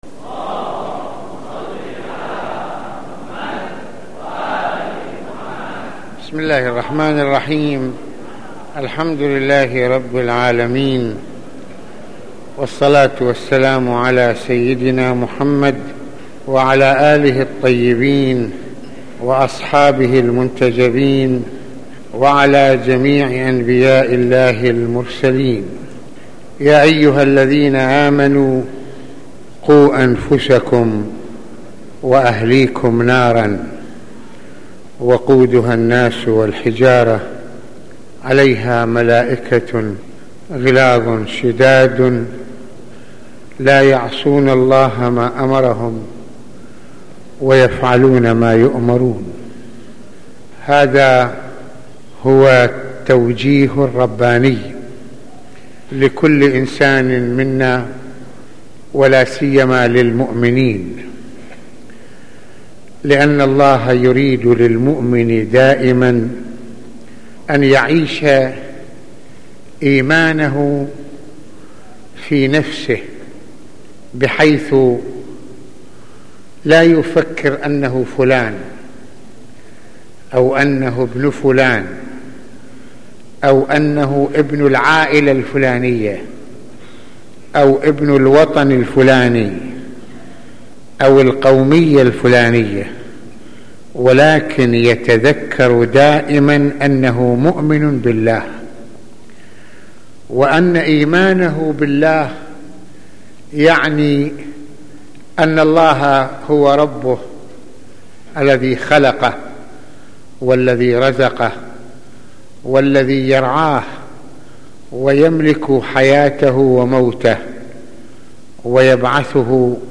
- المناسبة : موعظة ليلة الجمعة المكان : مسجد الإمامين الحسنين (ع) المدة : 30د | 18ث المواضيع : تفسير يا ايها الذين امنوا قوا انفسكم واهليكم نارا وقودها الناس والحجارة - لا يدخل الجنة نمام - حرمة التجسس على الناس - العصبية تؤدي الى النار - الكذابين الذين يكذبون عن السيد فضل الله..